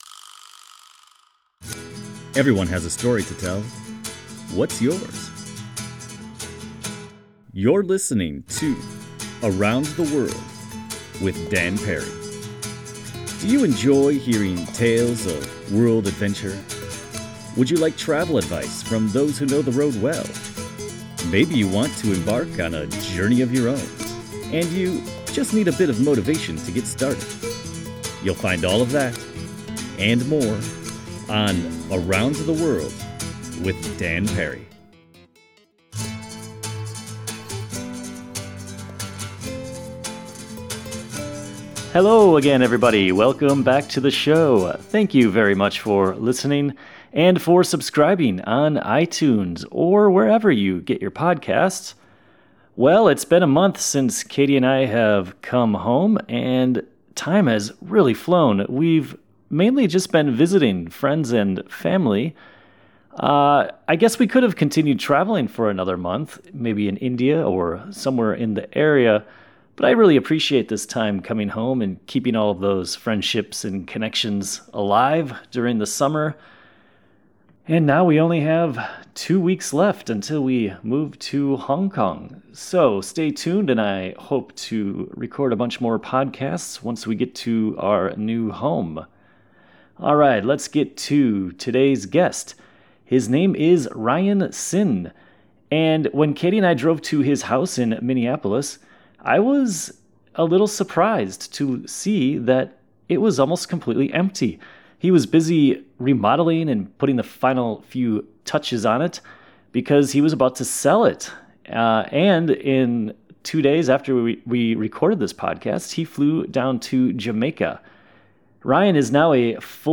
I had a great time chatting with him, and I hope you'll enjoy our conversation.